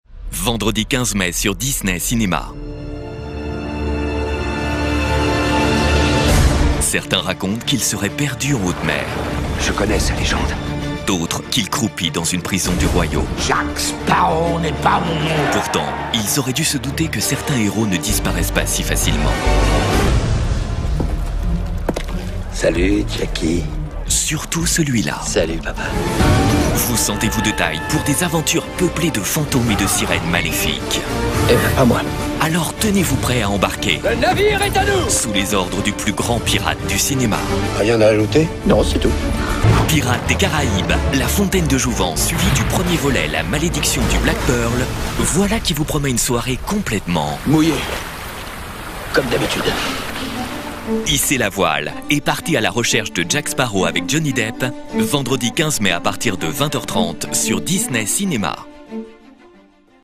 DISNEY CINEMA Jack Sparrow - Comédien voix off
Genre : voix off.